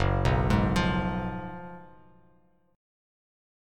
F#M7sus2sus4 chord